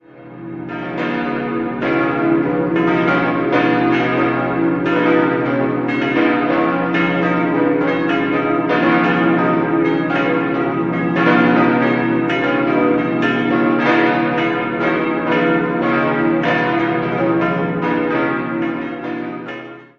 5-stimmiges Geläute: h°-cis'-dis'-eis'-gis' Alle Glocken wurden 1948 von Rudolf Oberascher in München gegossen.
Da die große Glocke aber einen Halbton zu hoch aus dem Guss kam und ganz bewusst nicht neu gegossen wurde, ergibt sich eine sehr interessante und zugleich ungewöhnliche Schlagtonlinie.